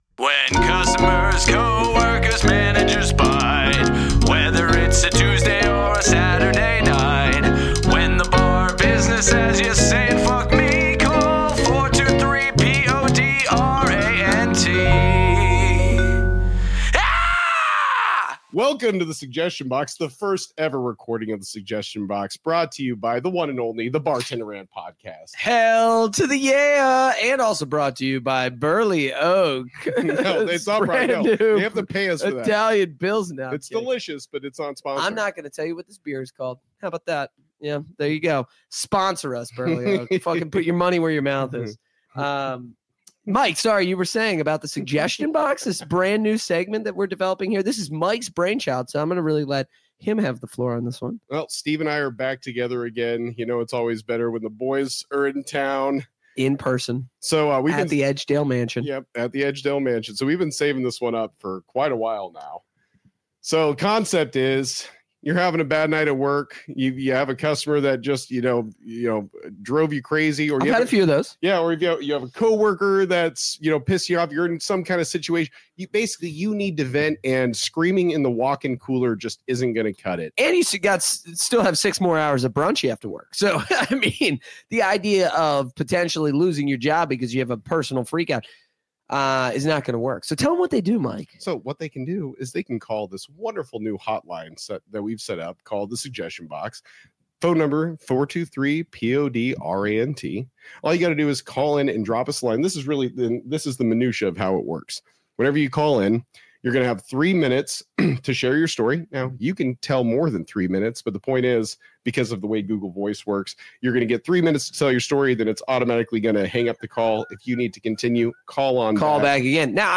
Be a guest on this podcast Language: en Genres: Comedy , Comedy Interviews Contact email: Get it Feed URL: Get it iTunes ID: Get it Get all podcast data Listen Now...